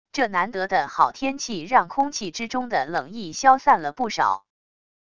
这难得的好天气让空气之中的冷意消散了不少wav音频